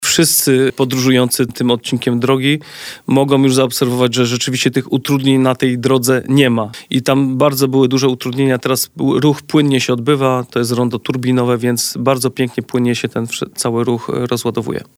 – To jest kluczowe miejsce, ponieważ to jest połączenie drogi krajowej z – tak naprawdę – dwoma powiatowymi. Zawsze tutaj mieliśmy taki, powiedzmy w cudzysłowie, węzeł gordyjski. Teraz ta sytuacja jest rozwiązana. Sprawdziły się przewidywania Generalnej Dyrekcji Dróg Krajowych i Autostrad. Były wykonywane badania obciążenia maksymalnego ruchu i rzeczywiście to rondo rozładowuje cały ten ruch pojazdów, który jest na drodze krajowej – komentuje Paweł Zemanek, wójt gminy Porąbka.